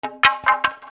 長い角材 垂木を落とす